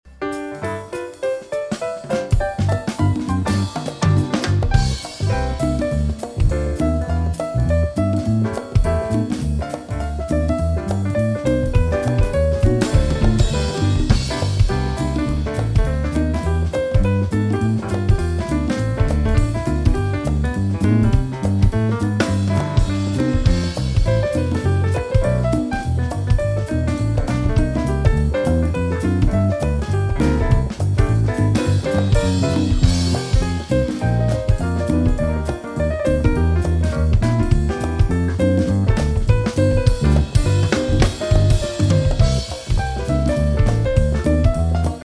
Mp3 Instrumental Song Download
Downloadable Instrumental Track